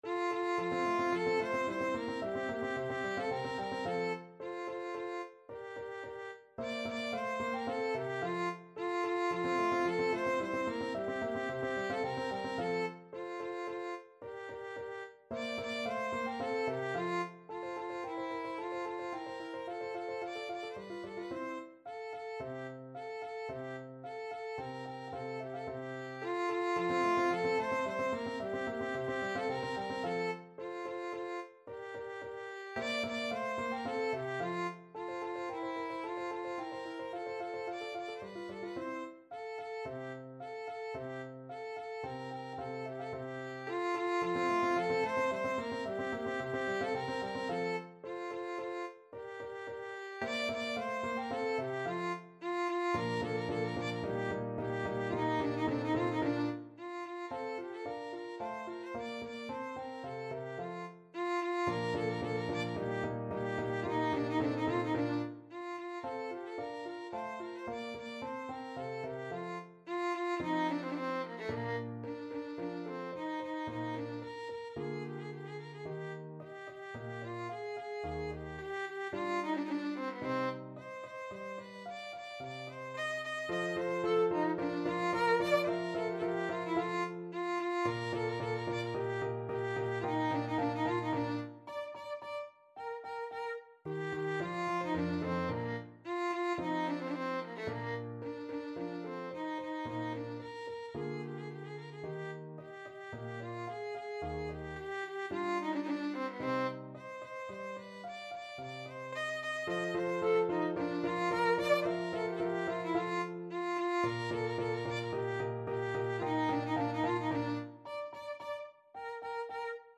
2/4 (View more 2/4 Music)
A4-Eb6
Vivace assai =110 (View more music marked Vivace)
Classical (View more Classical Violin Music)